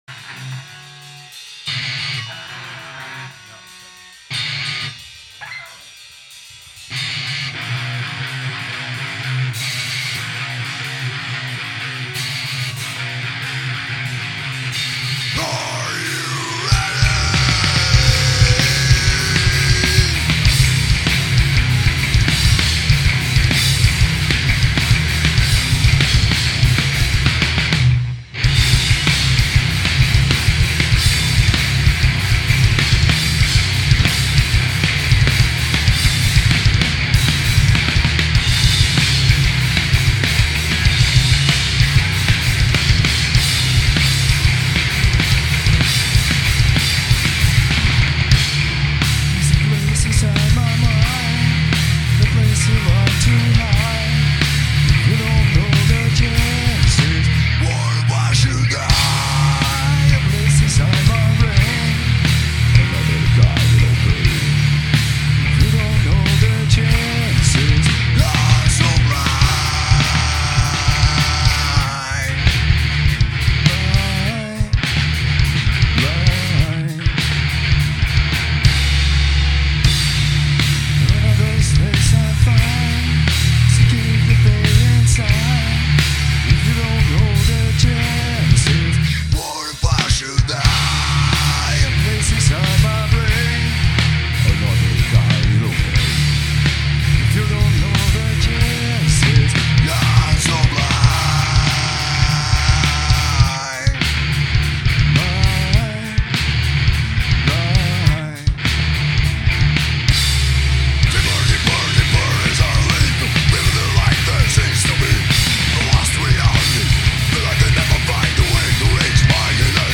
EstiloNew Metal